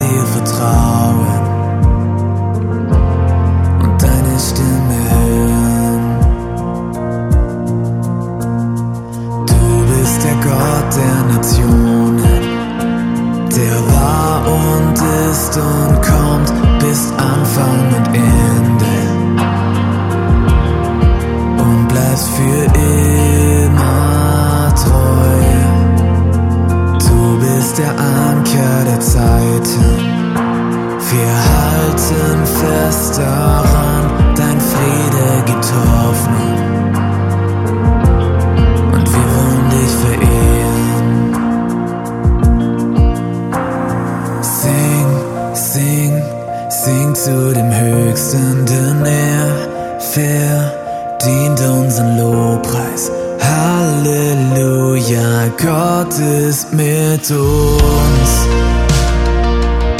Kraftvoll und intensiv.
Die Songs tragen ein neues Gewand im singbaren Stil.
Gesang.